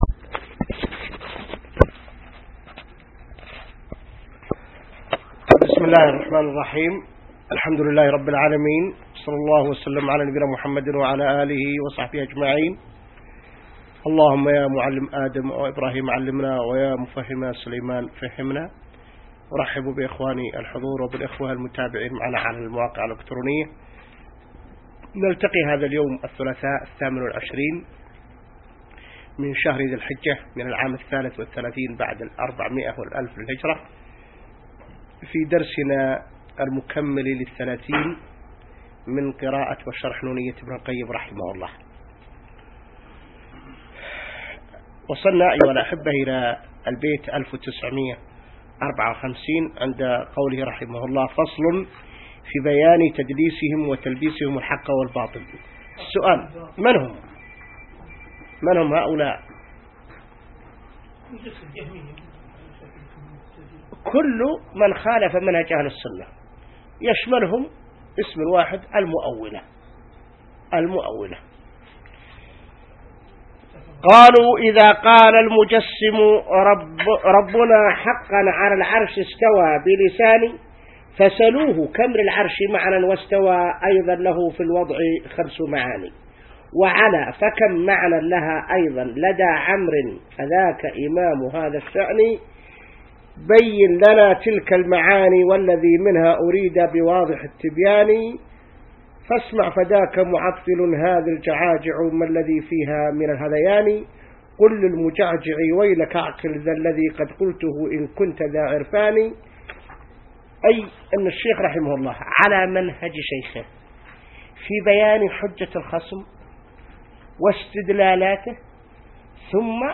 الدرس 30 من شرح نونية ابن القيم | موقع المسلم